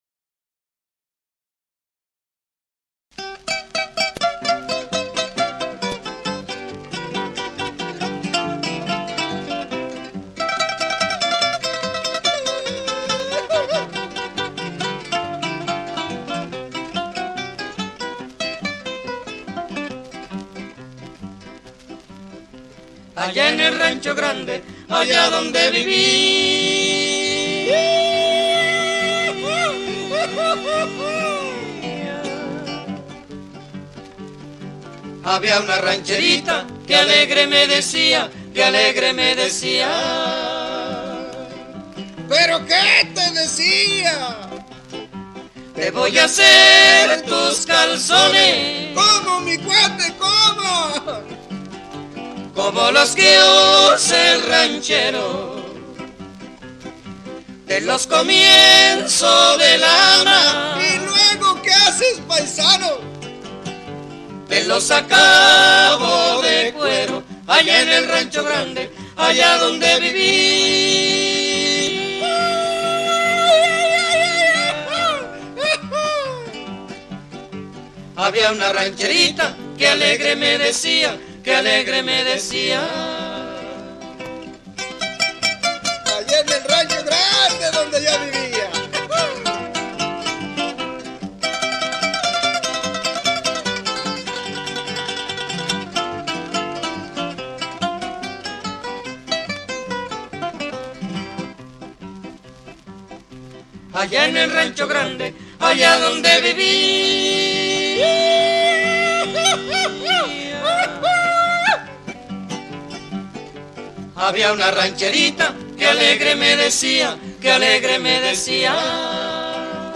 High Fidelity recording